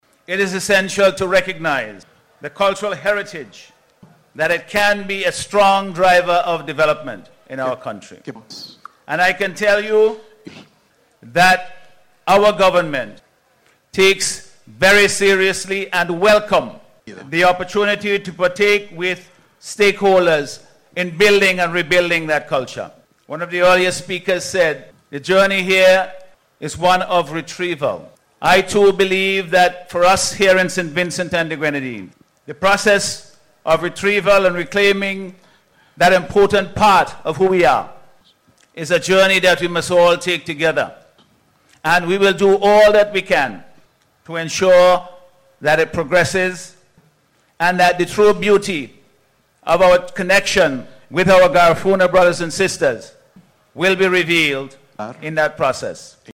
The Prime Minister gave the commitment, during his remarks at a Welcome Reception, held last night for the delegates who are here for the Garifuna Homecoming 2026.